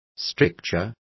Complete with pronunciation of the translation of stricture.